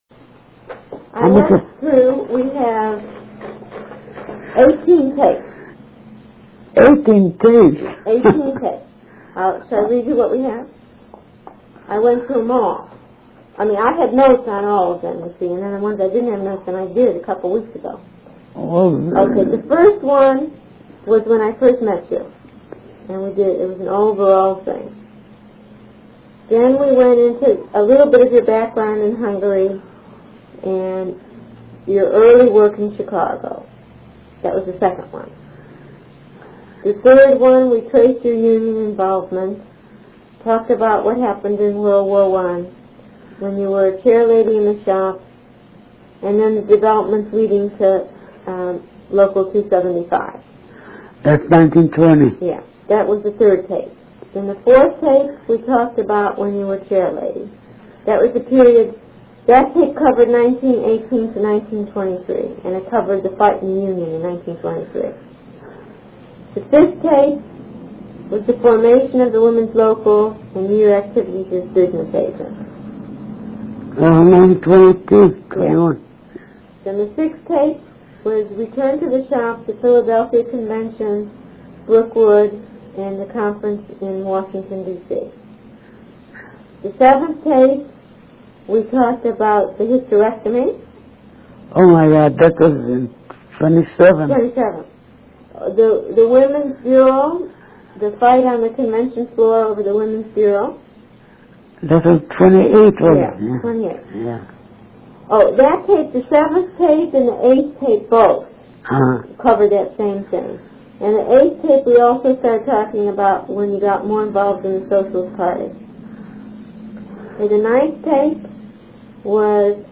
Compared to the earlier eighteen interviews, her energy level was very low, and she seemed to lack enthusiasm about doing the interview.